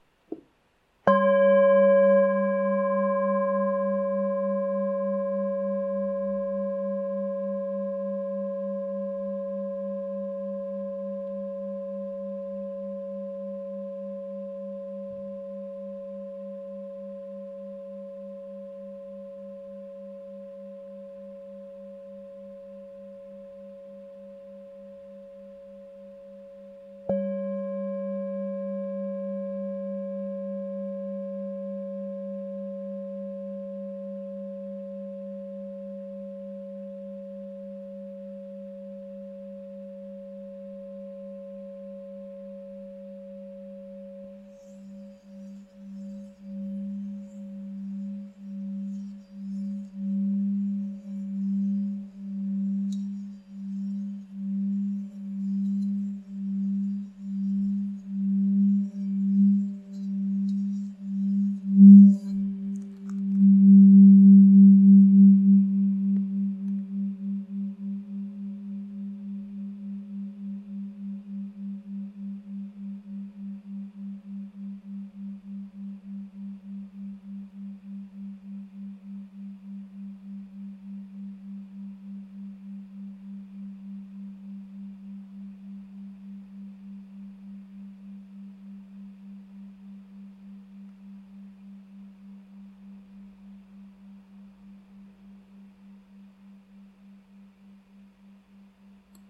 Campana tibetana contemporanea , creata con l’antica tradizione tibetana in lega dei 7 metalli.
Nota Armonica DO(C) #5 563 HZ
Nota di fondo SOL(G) 3 196 HZ
Campana Tibetana Nota SOL(G) 3 196 HZ